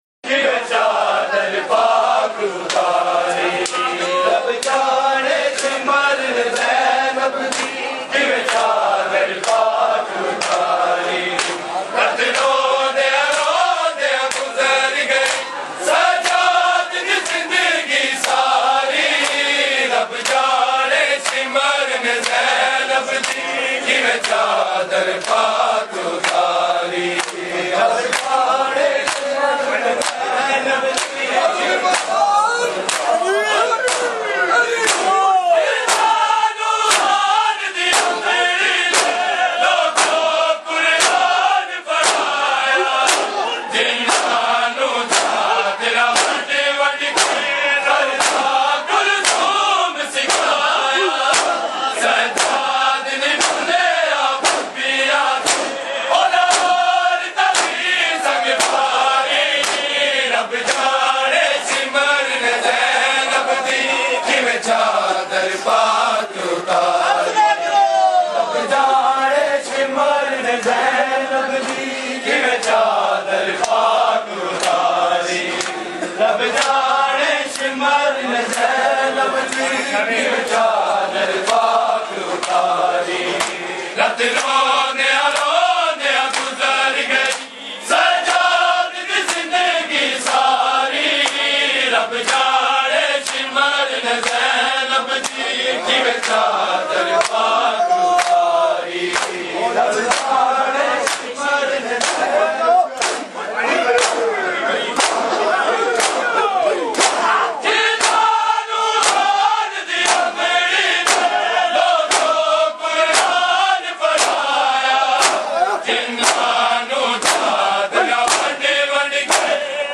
Markazi Matmi Dasta, Rawalpindi
Recording Type: Live
Location: Rawalpindi, Sarafa Bazar